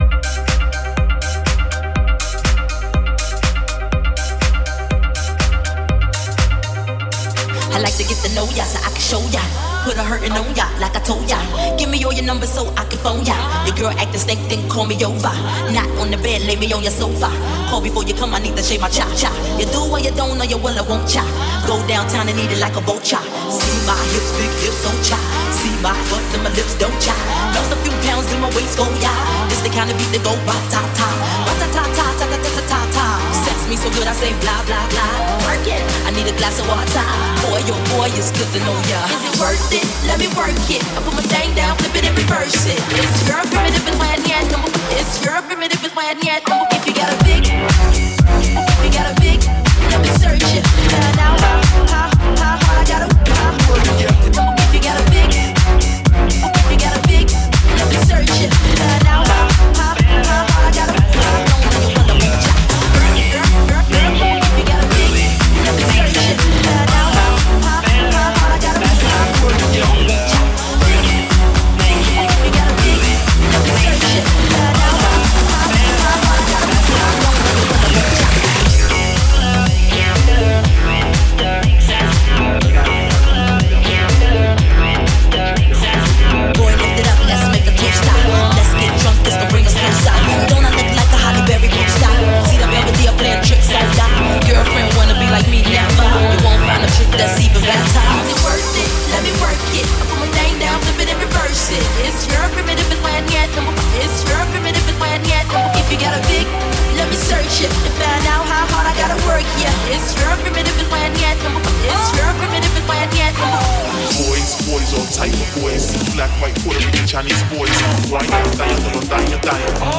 Genres: Country, Hip Hop, Rock, Top 40